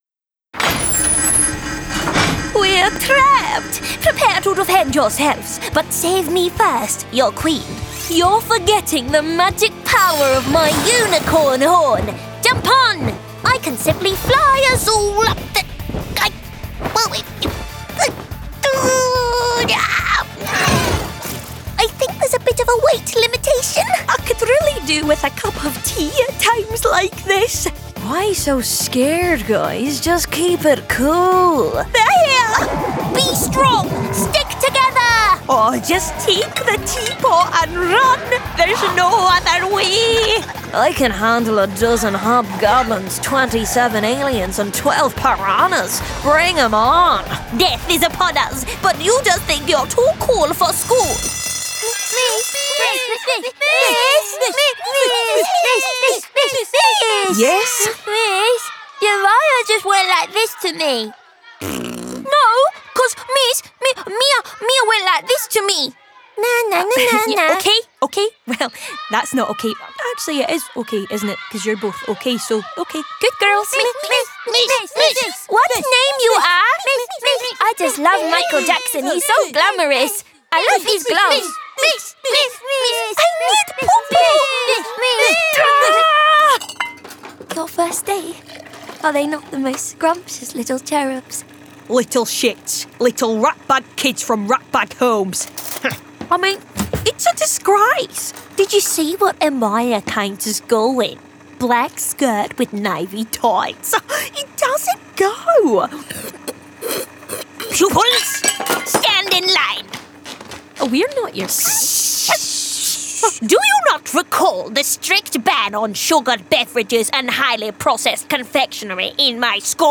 Character & Games Reel
American, Australian, RP ('Received Pronunciation'), Scottish, South London, Various
Character, Versatile, Acting, Confident, Games